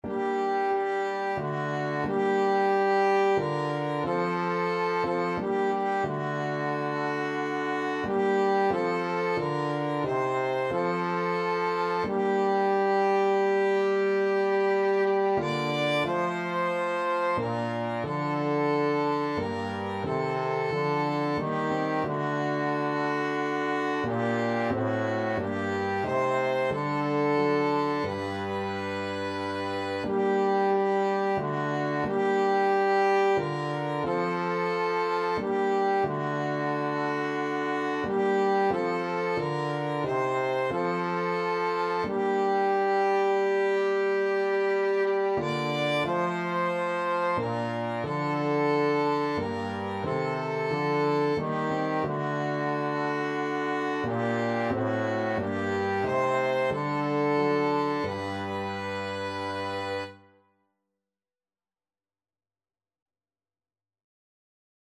Free Sheet music for Flexible Ensemble and Piano - 2 Players and Piano
Violin
CelloTrombone
Piano
3/4 (View more 3/4 Music)
G minor (Sounding Pitch) (View more G minor Music for Flexible Ensemble and Piano - 2 Players and Piano )